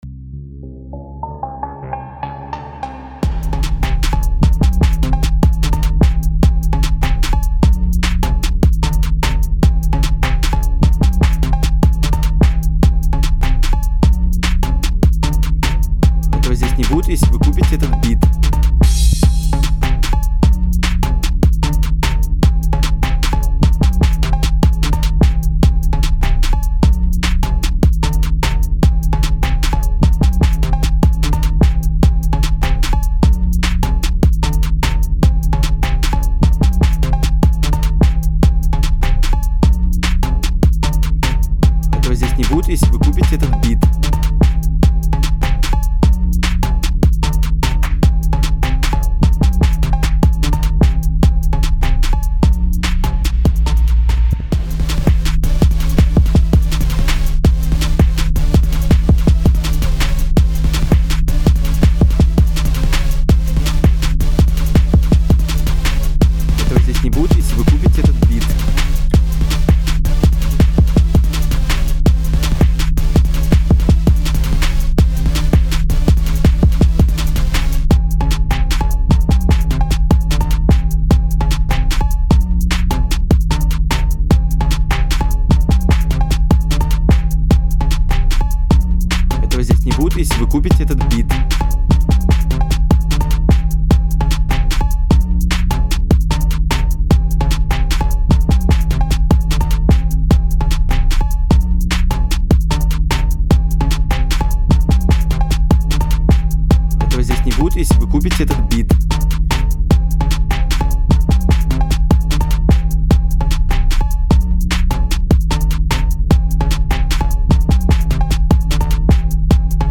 Hip-hop Энергичный 145 BPM